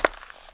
Index of /90_sSampleCDs/AKAI S6000 CD-ROM - Volume 6/Human/FOOTSTEPS_1
CONCRETE A.WAV